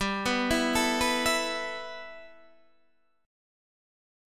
G6add9 chord